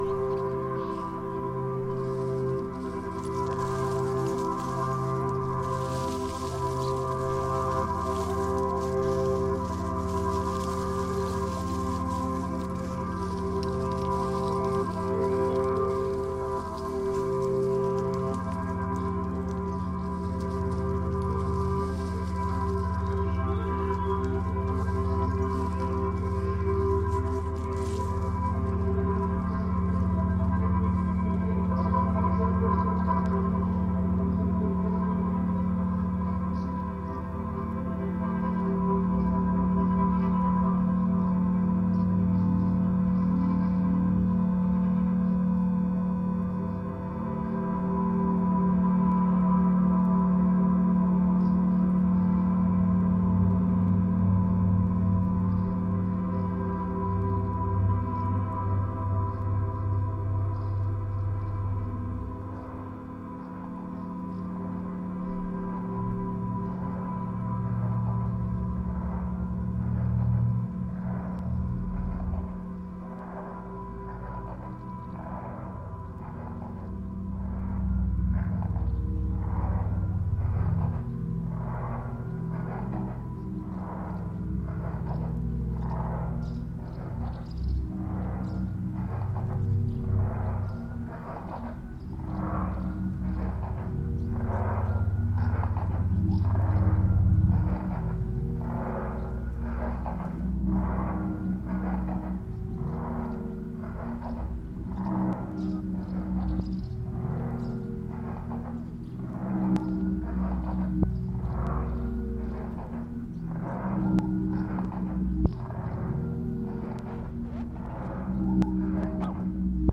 cinco faixas de fusão entre natural e artificial